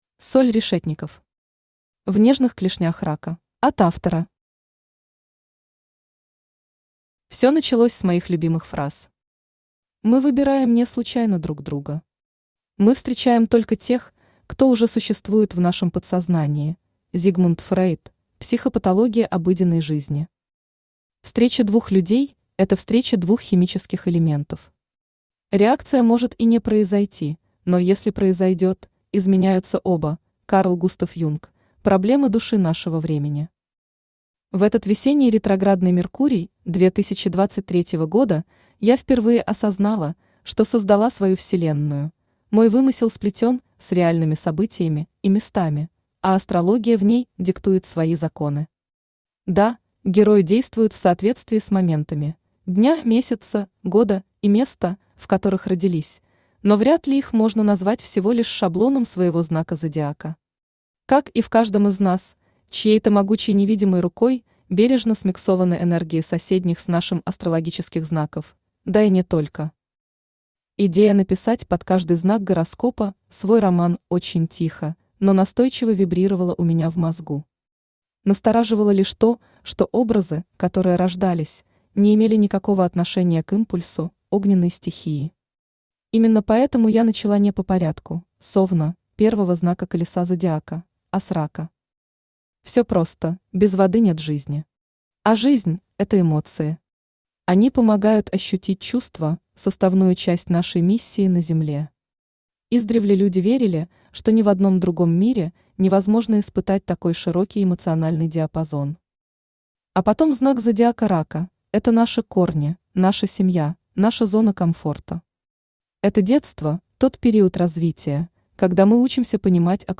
Аудиокнига В нежных клешнях Рака.
Автор Соль Решетникоф Читает аудиокнигу Авточтец ЛитРес.